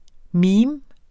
Udtale [ ˈmiːm ]